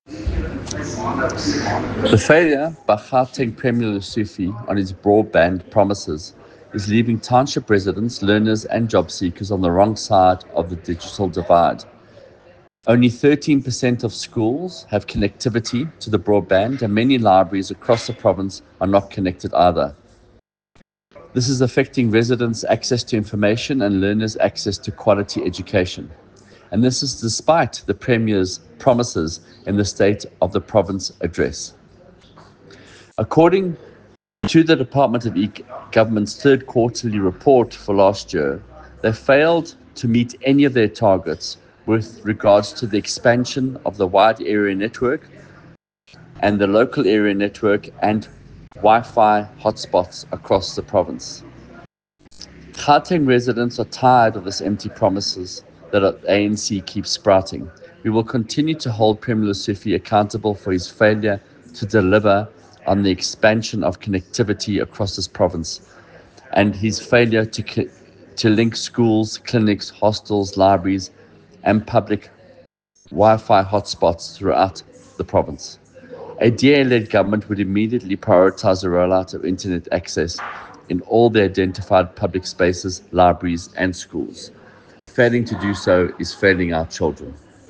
Note to Editors: Please find a soundbite in English by DA MPL, Michael Waters